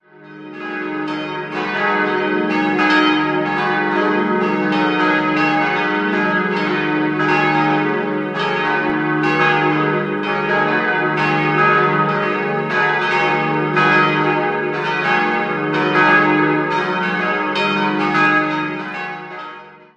Eine der Hauptsehenswürdigkeiten im Inneren ist das steinerne gotische Sakramentshäuschen von 1470 im Chorraum. 4-stimmiges ausgefülltes E-Moll-Geläute: e'-g'-a'-h' Laut Kirchenführer wurden die vier Glocken 1950 von Karl Czudnochowsky in Erding gegossen.